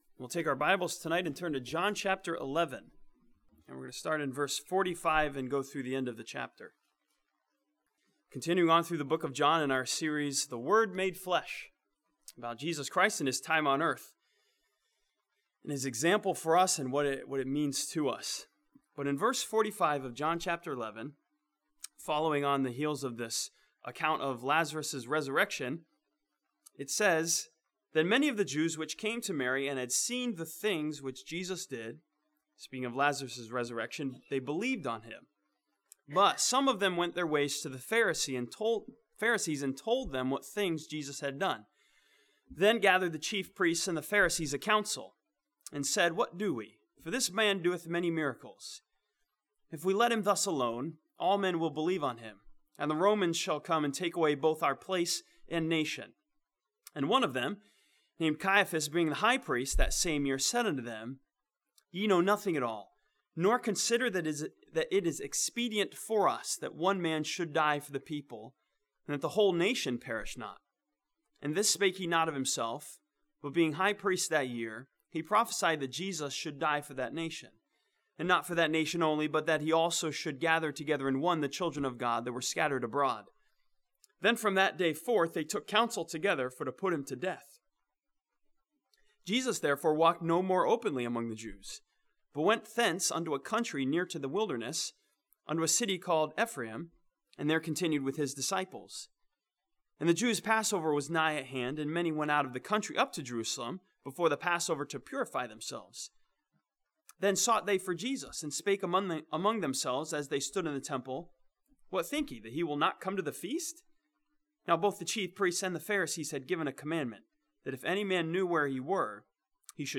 This sermon from John chapter 11 challenges believers tonsider the results when we say 'no" to the work of Jesus Christ in us.